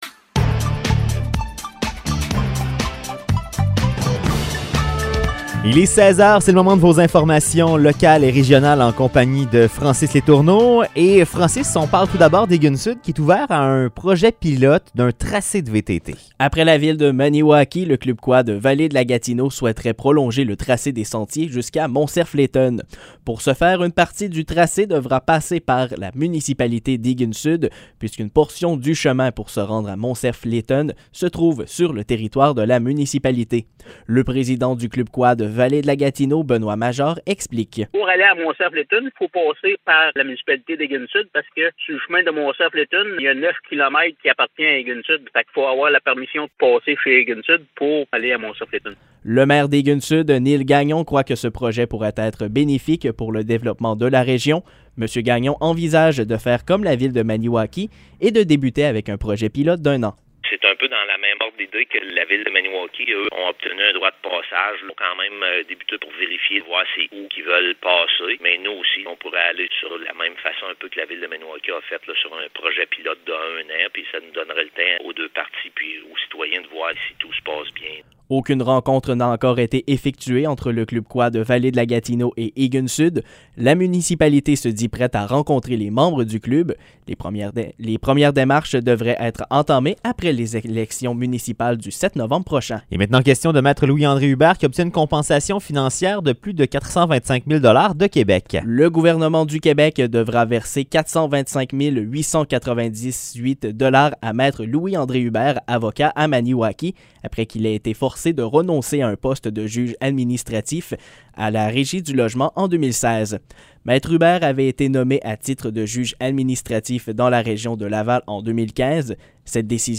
Nouvelles locales - 18 octobre 2021 - 16 h